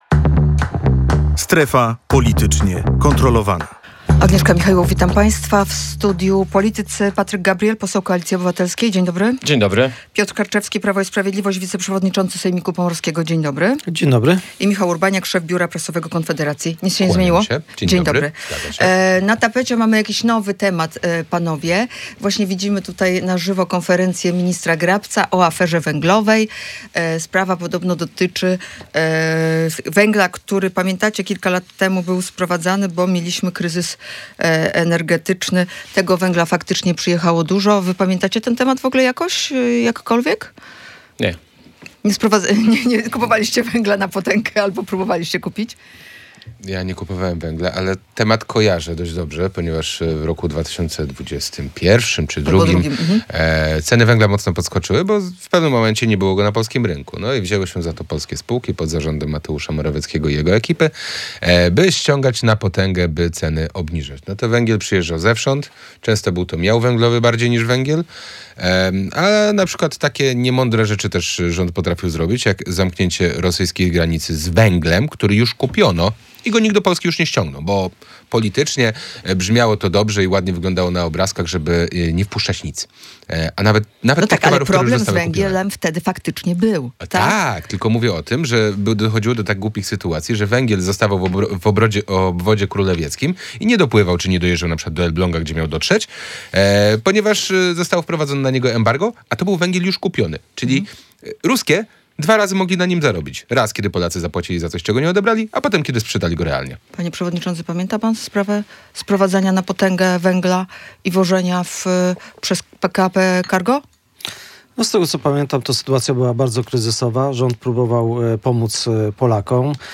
,,Głosowanie przeciw programowi SAFE to działanie na szkodę kraju ” kontra ,,To pożyczka obarczona zbyt dużą warunkowością” – tak politycy w studiu Radia Gdańsk komentowali ewentualne przystąpienie Polski do pożyczki na dofinansowanie armii o rozwój polskiego przemysłu.